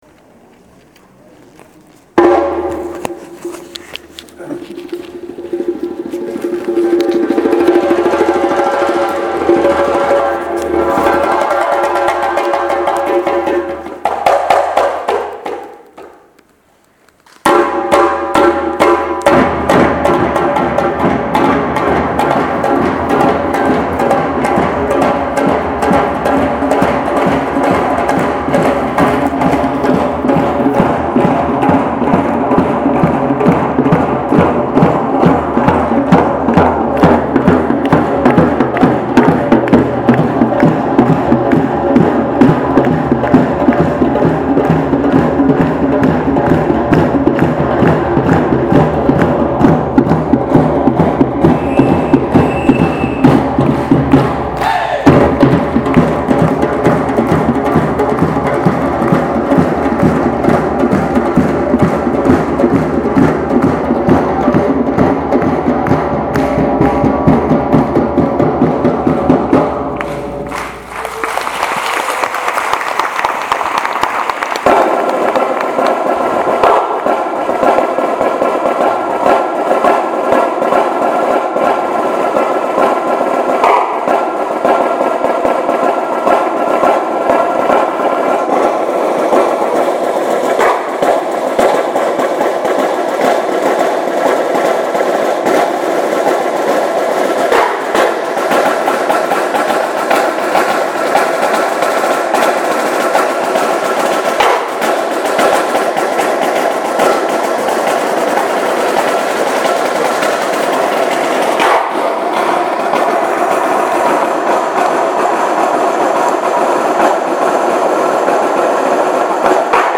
パーカッション
しかしながら、太鼓のような打楽器だけで、演奏する分野があるそうである。
音だけ聞いている限り打楽器がなっているようである。
思わず観客は手を叩いたり、足踏みして音を出したりする。
percussion.mp3